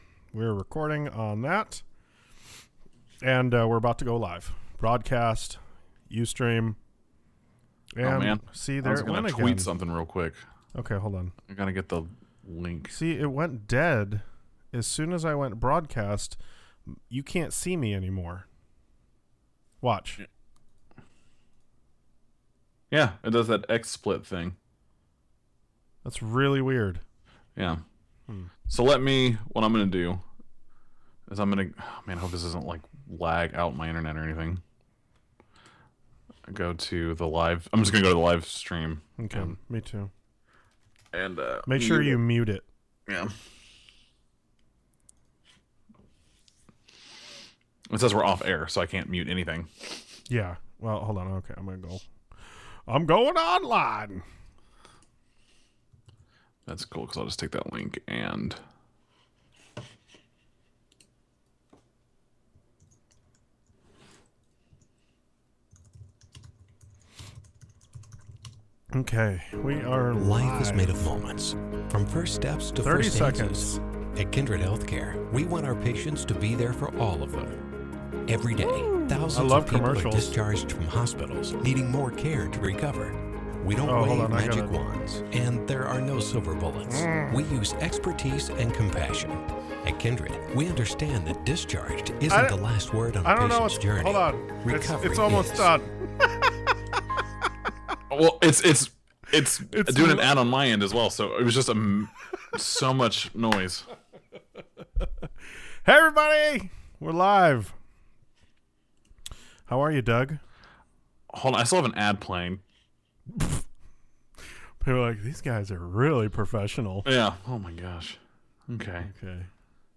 We are back live on this show.